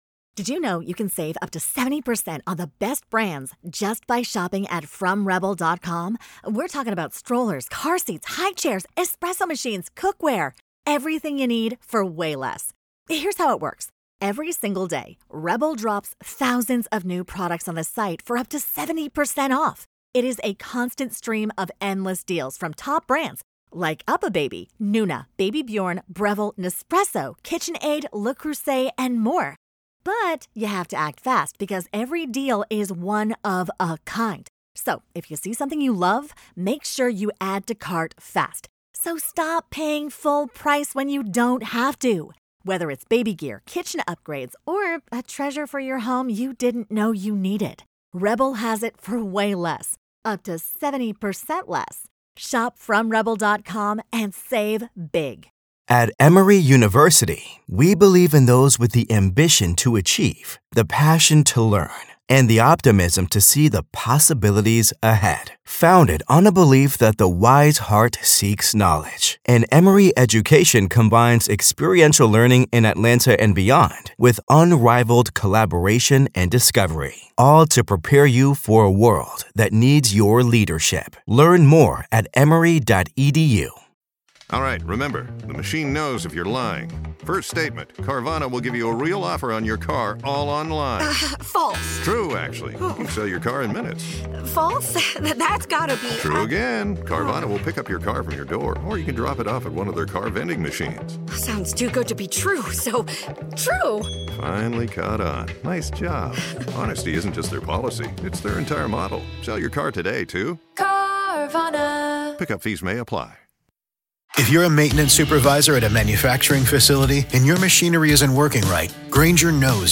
This episode pulls back the curtain on the psychology of targeted violence—and why so many communities only connect the dots when it’s already too late. 🔔 Subscribe for more true crime interviews, forensic breakdowns, and expert-driven analysis from inside the nation’s most disturbing cases.